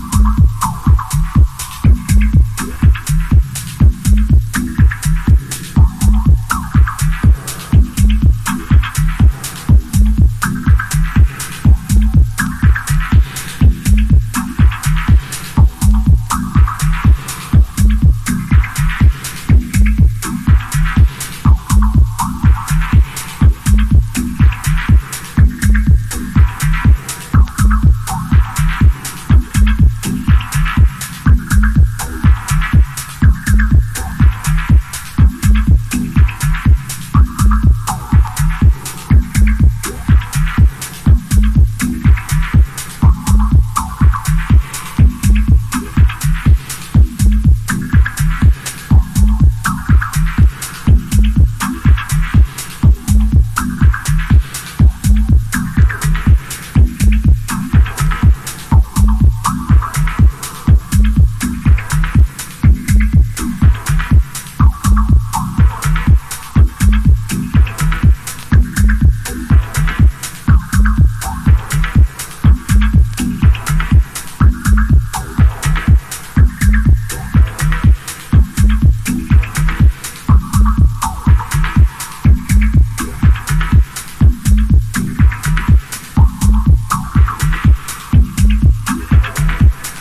近作の延長にあるようなタイトなリズムの中、深海にいるような心地にさせられるミニマルダブ・テクノの最高峰。